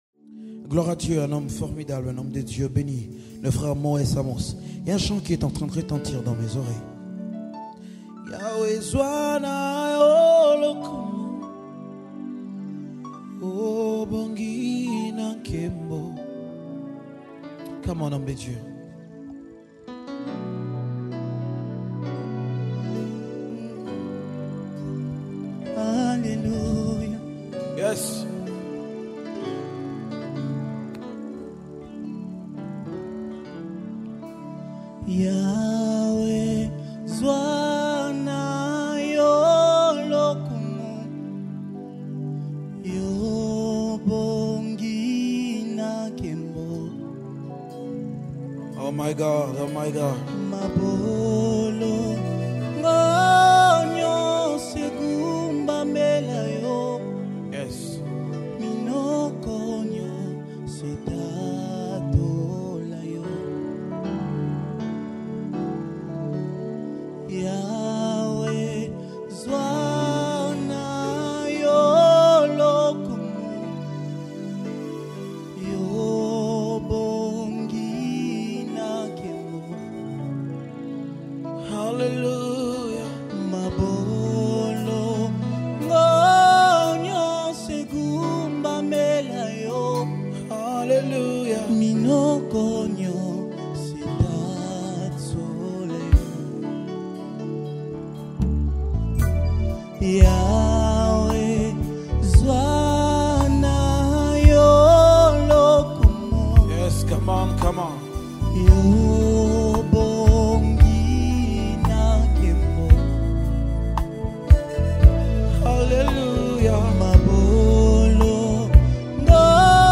Gospel 2022